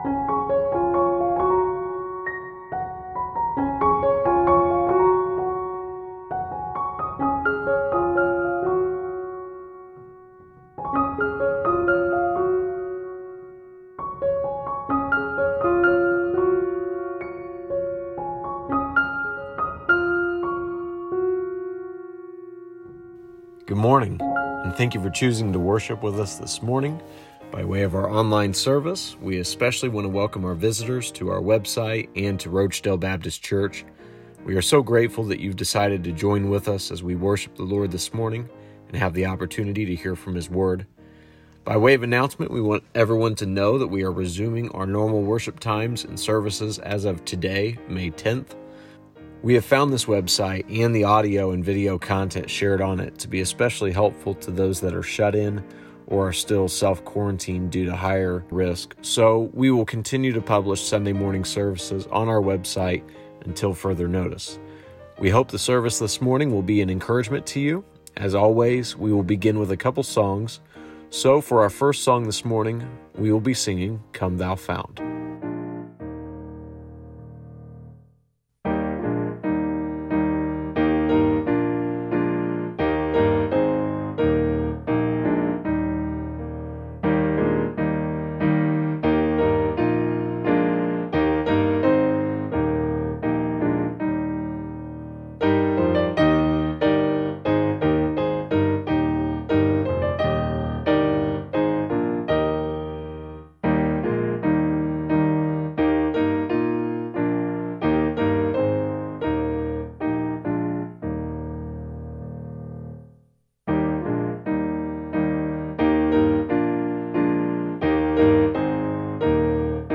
Hymns